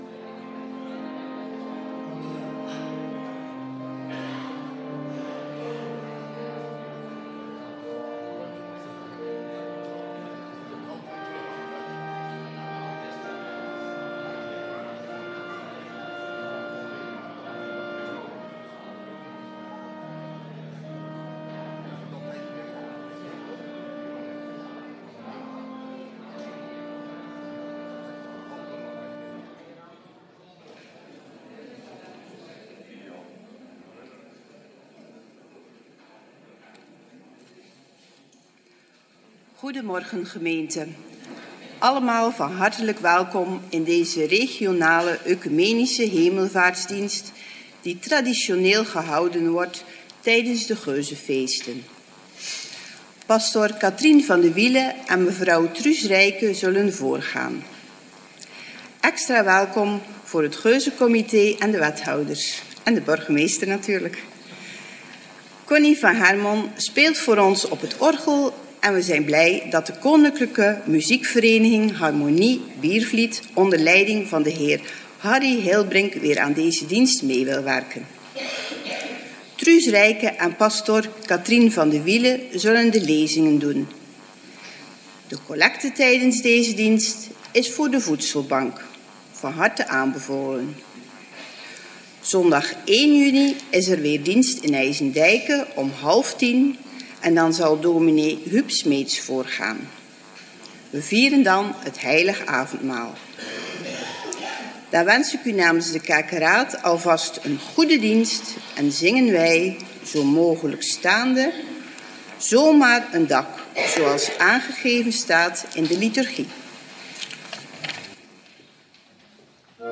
Regionale oecumenische Hemelvaartsdagviering
LITURGIE voor de regionale oecumenische Hemelvaartsdagviering in de P.K.N. kerk van Biervliet 29 mei 2025